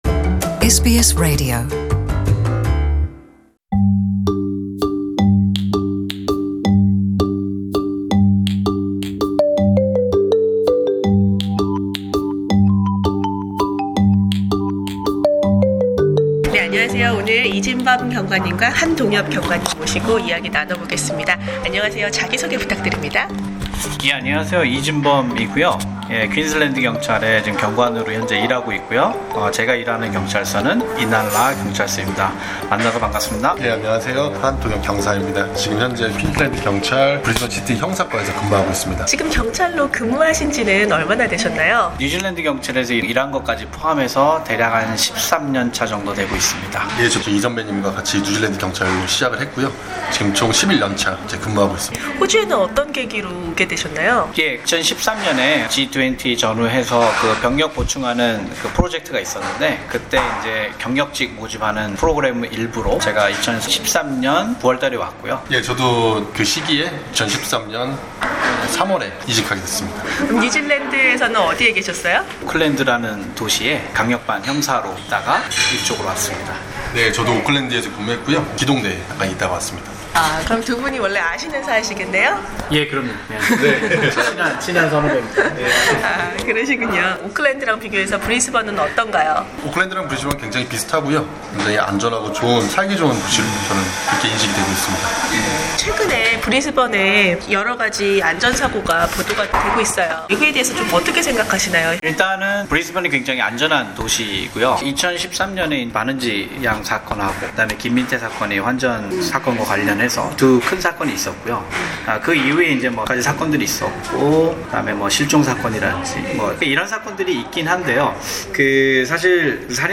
[The full interview available on the podcast above] Share